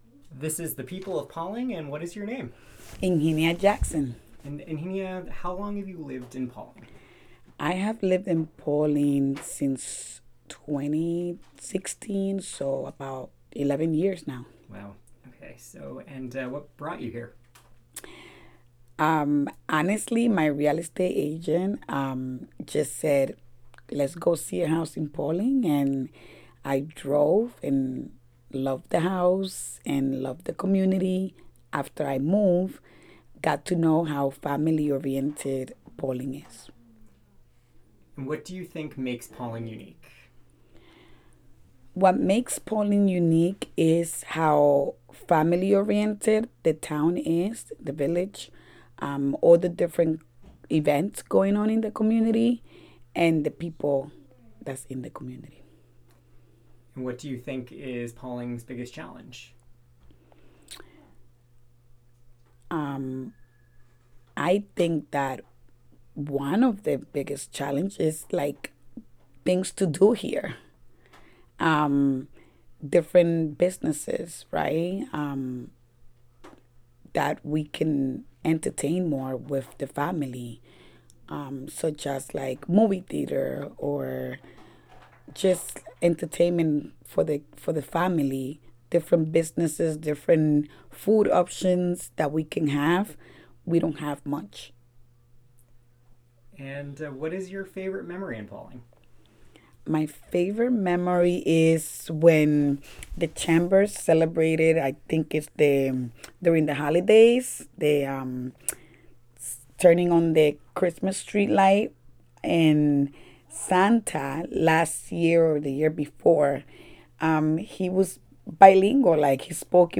The conversation was recorded as part of the People of Pawling Project. The project saught to create brief snapshots of Pawling that could be easily consumed and show the richness of the community during the time that the recordings were done.